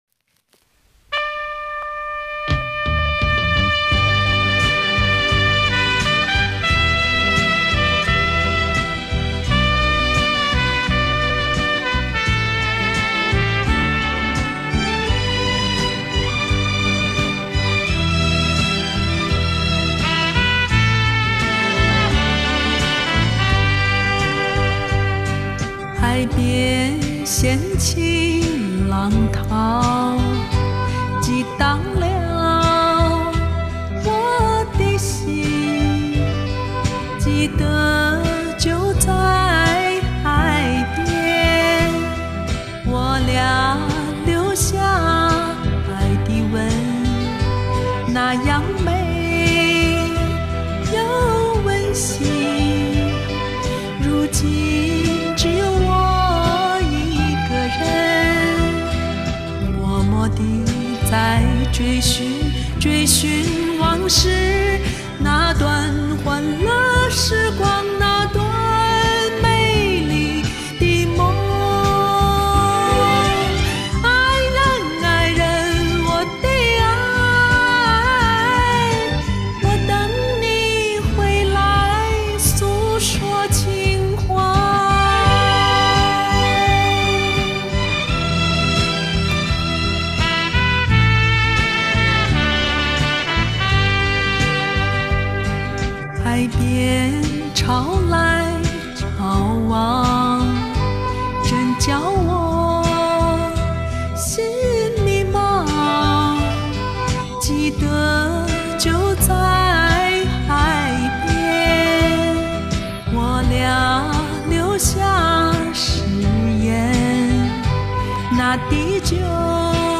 现在自己的音色气息咬字啥的都有了很大进步，终于敢唱给别人听了。